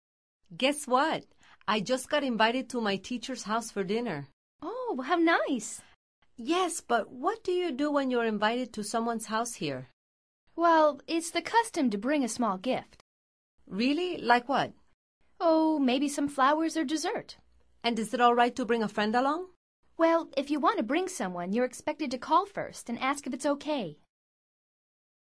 Activity 51: Antes de escuchar la conversación observa atentamente la imagen y trata de responder las tres preguntas.
Escucha el audio y concéntrate en la entonación y ritmo de las frases.